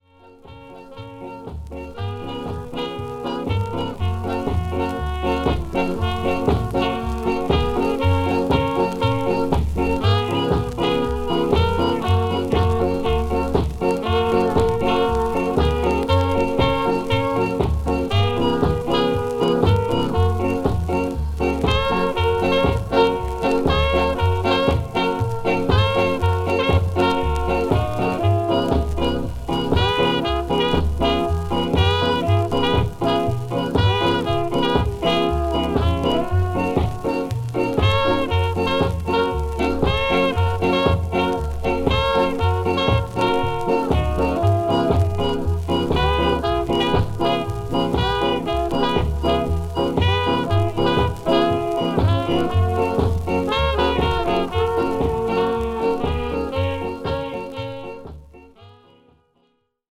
再生は良好です。